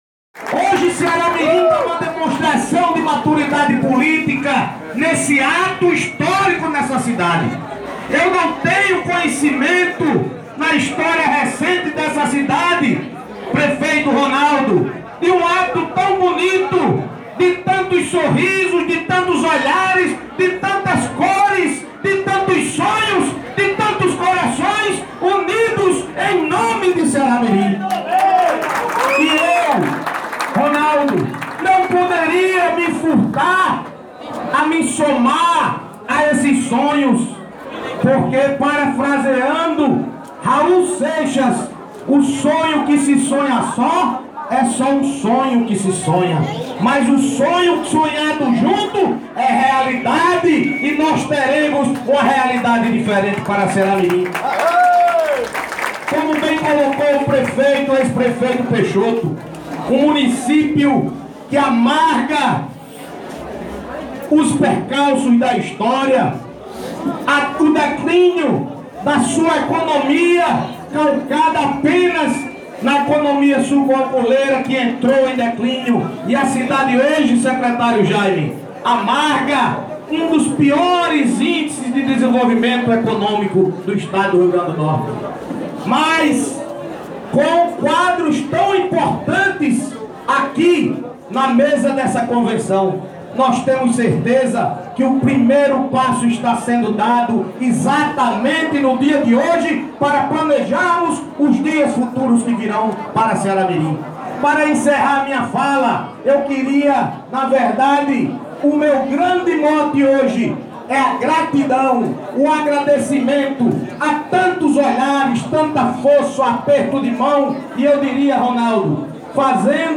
O evento aconteceu na escola estadual Ubaldo Bezerra de Melo e contou com a presença diversas lideranças políticas e da população.